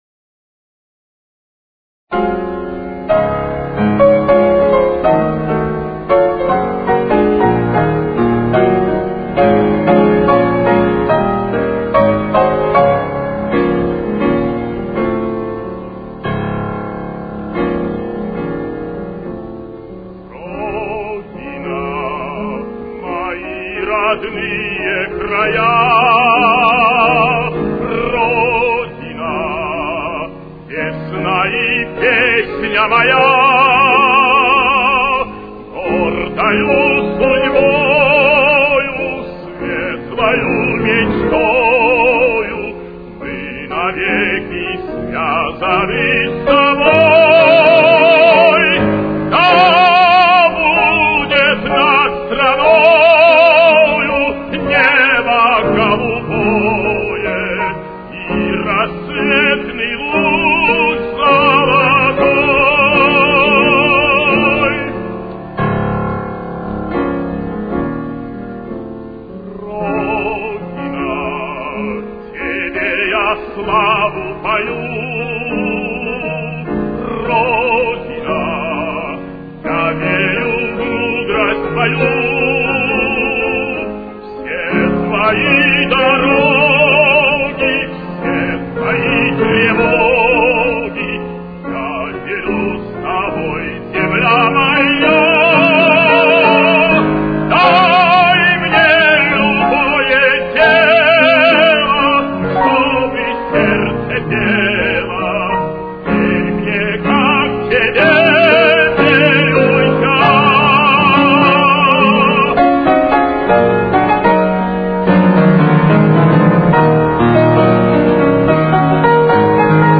советский российский оперный певец (баритон).